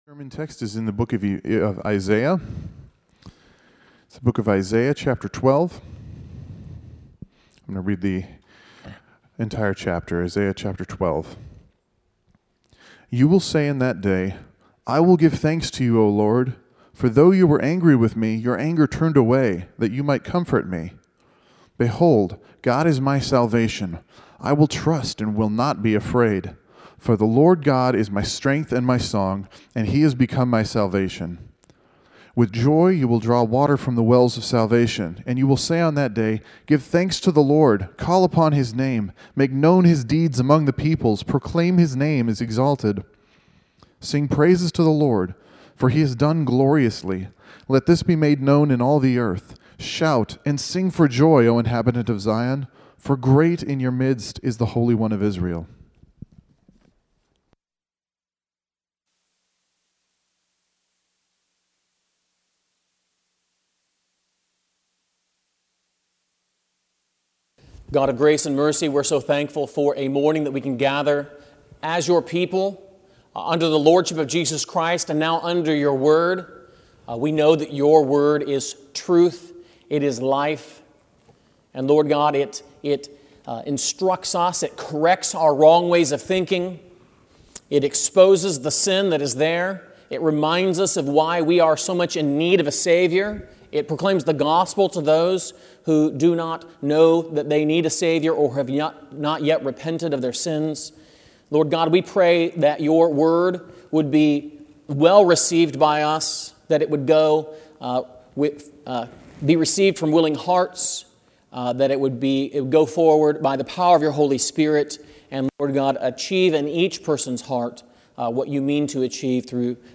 Sermons from the Book of Isaiah